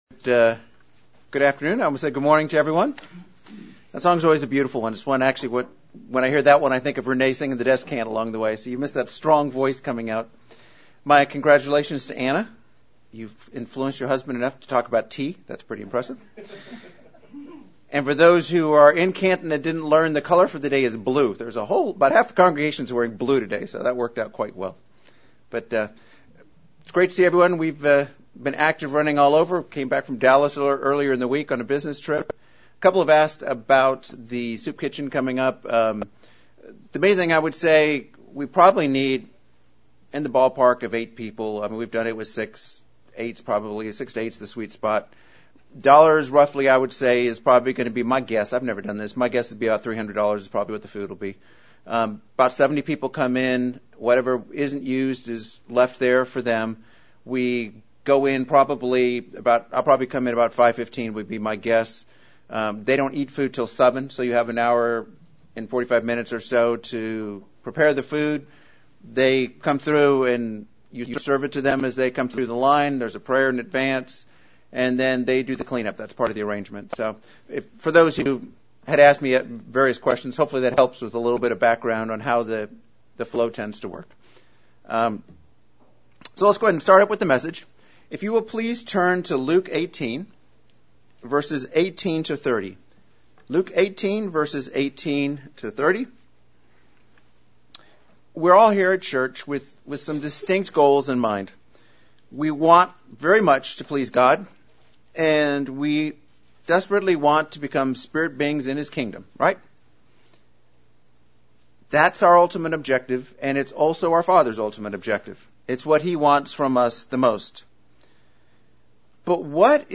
What is the difference between surrender and sumission? This sermon examines the depth of total surrender to God.